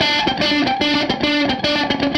AM_HeroGuitar_110-D01.wav